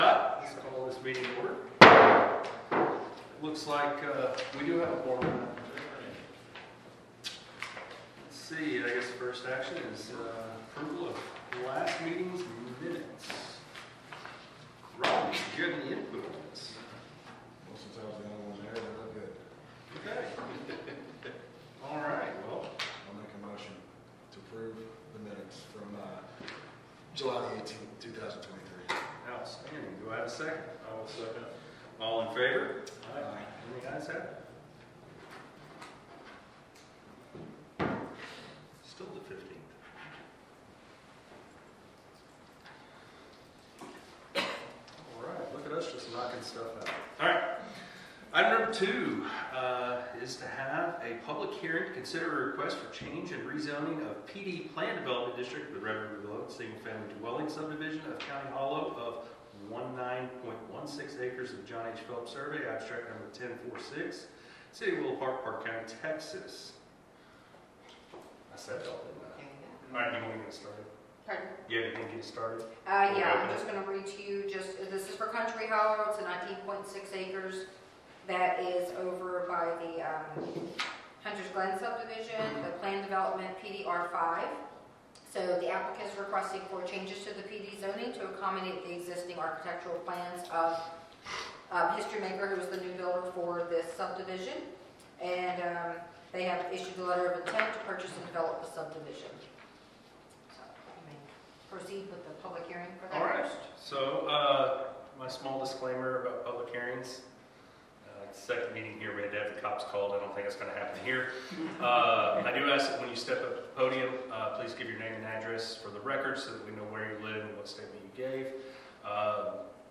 • Location and Time: El Chico City Hall, 120 El Chico, Suite A, Willow Park, Texas 76087, 6PM